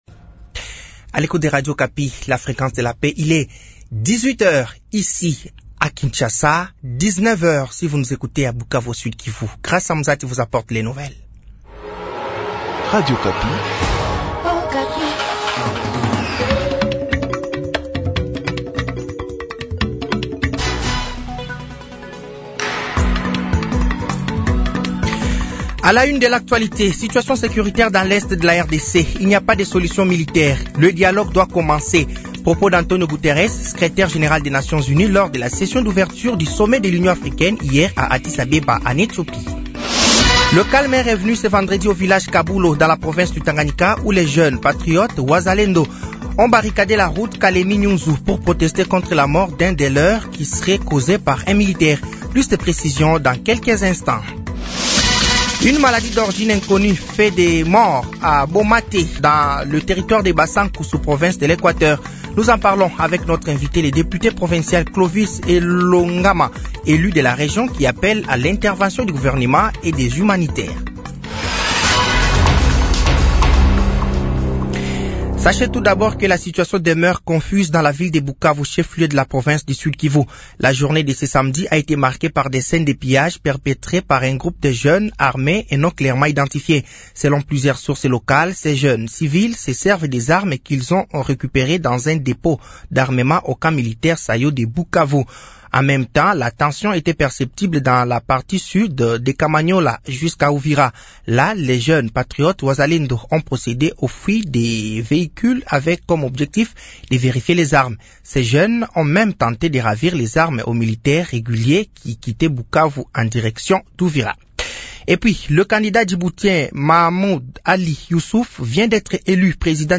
Journal français de 18h de ce samedi 15 février 2025